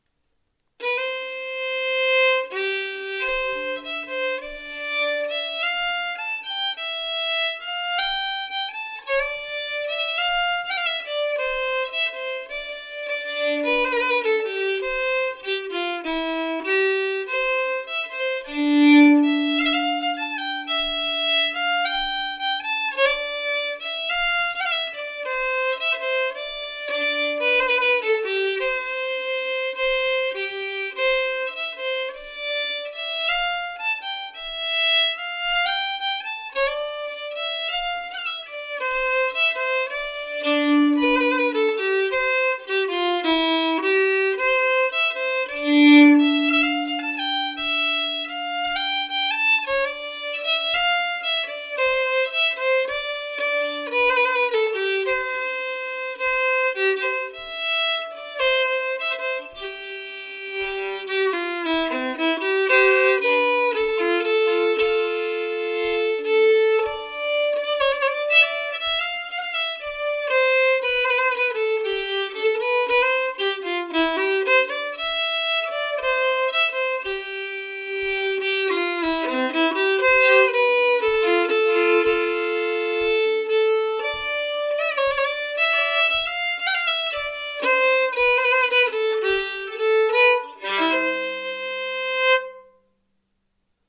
En brudmarsch i C skriven av Pers Erik Olsson (1912-1983) från Östbjörka i Rättvik.
Östbjörka-brudmarsch.mp3